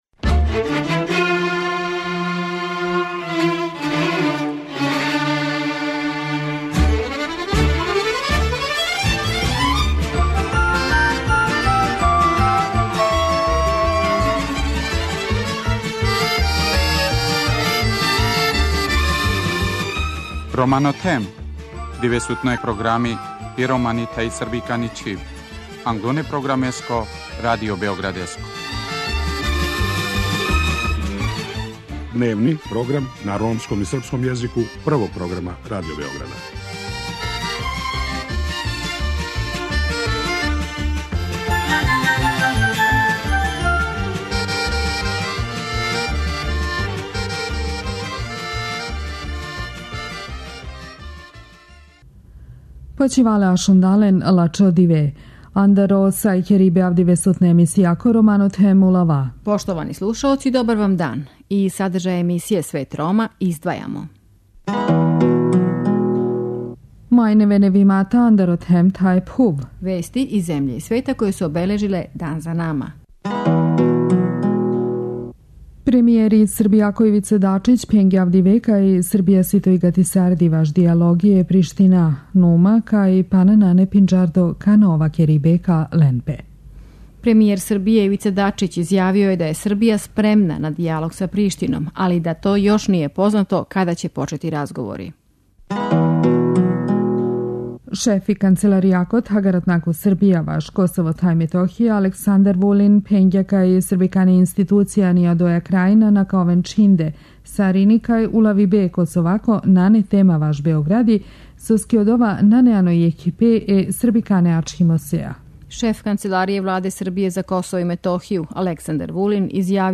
У вечерашњој емисији емитујемо разговор са амбасадором Шведске у Београду Кристером Аспом о директиви Европске Уније која обухвата забрану повратка на територију Шенгена које од 1. маја ова земља спроводи.